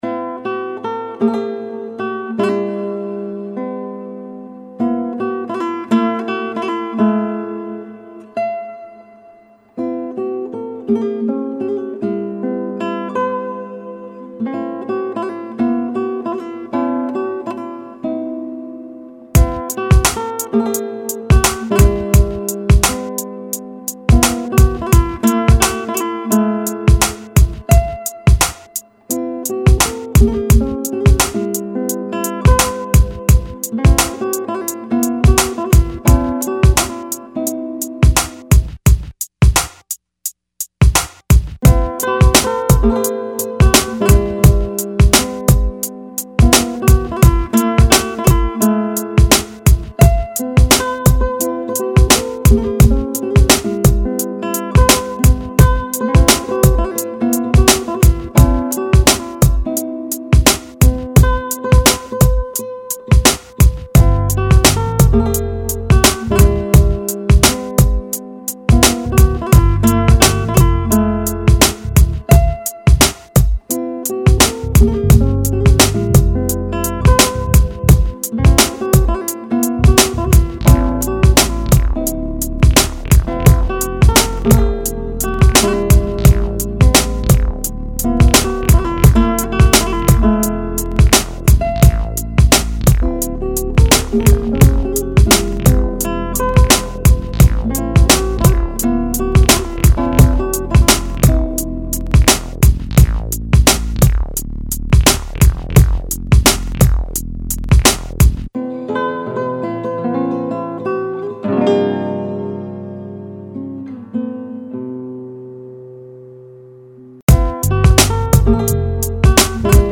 2006 Рэп Комментарии
молодая рэп группа